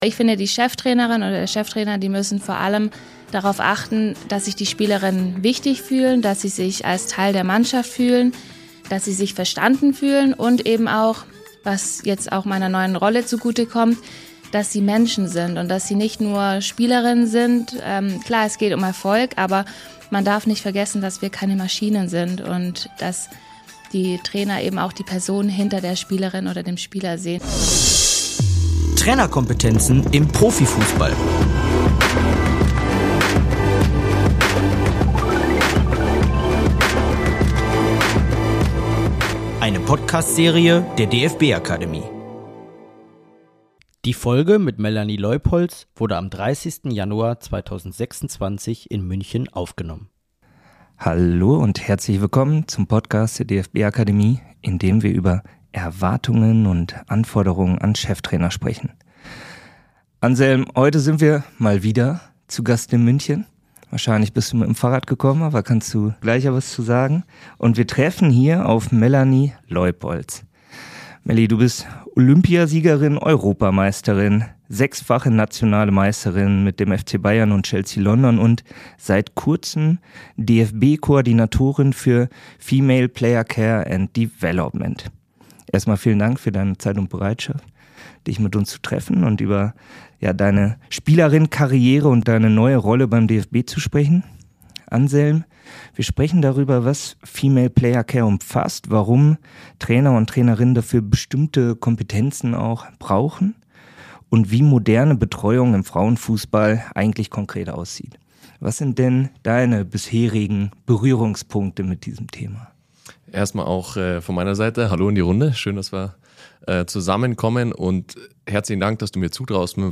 Außerdem erklärt sie, wie Dual-Career-Angebote (Studium, Workshops, finanzielle Bildung) Spielerinnen während und nach der Karriere unterstützen können – und wo Deutschland im internationalen Vergleich noch Entwicklungspotenzial hat. Die Folge wurde 30. Januar 2026 in München aufgenommen.